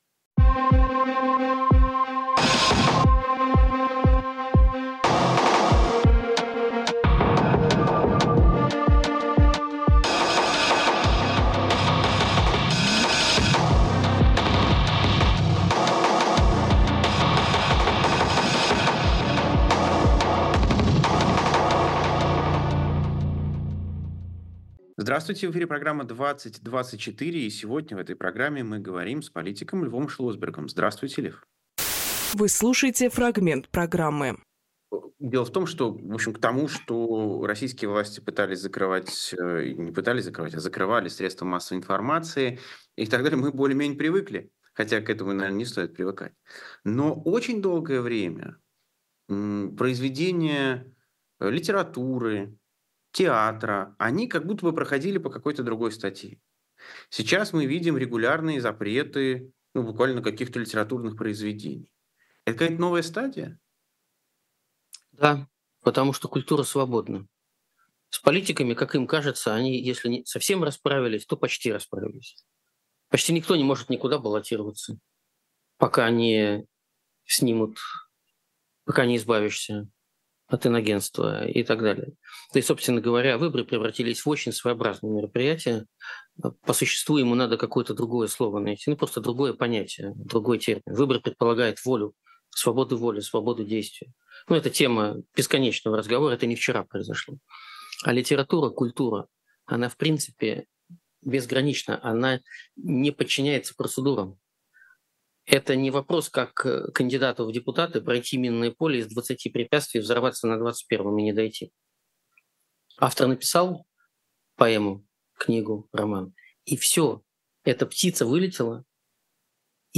Фрагмент эфира 12.07.24